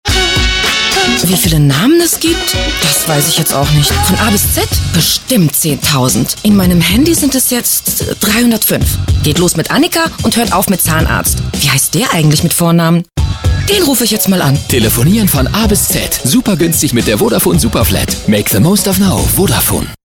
Sprechprobe: Industrie (Muttersprache):
german female voice over talent.